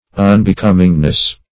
Un`be*com"ing*ness, n.